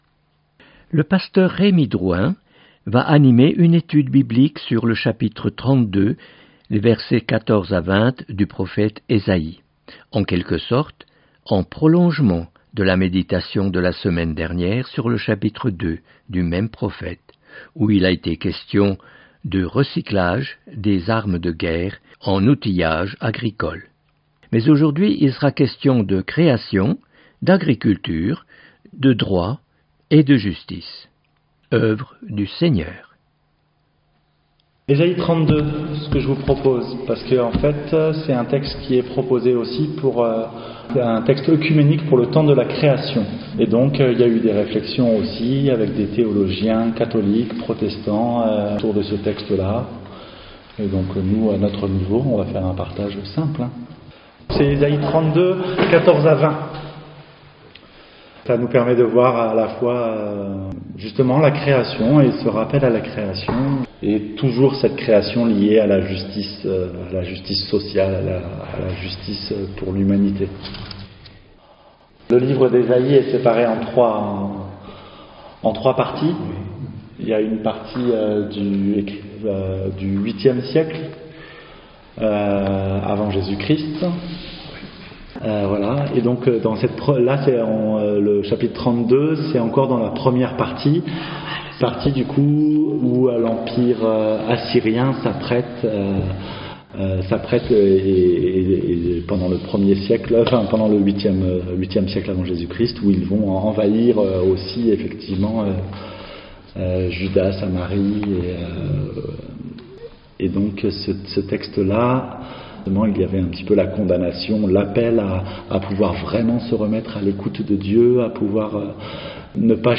Etude biblique sur Esaïe 32/14-20 animée par le pasteur